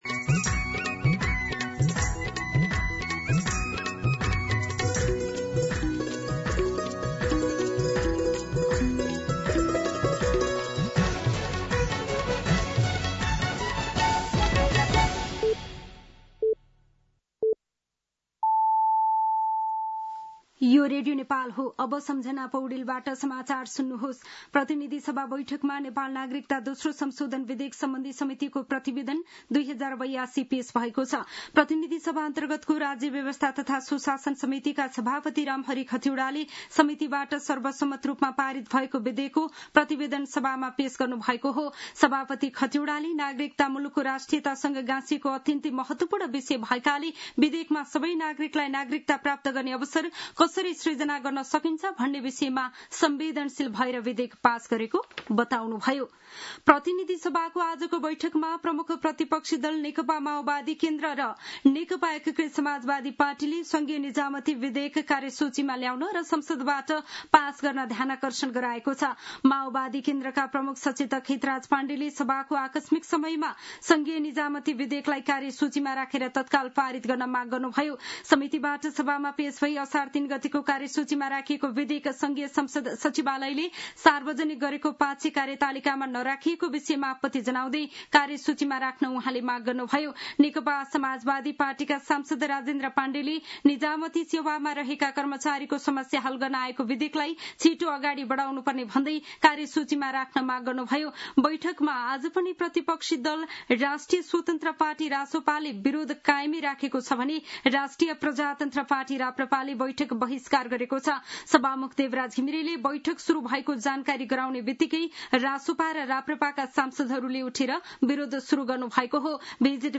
दिउँसो ४ बजेको नेपाली समाचार : ५ असार , २०८२
4-pm-Nepali-News-1.mp3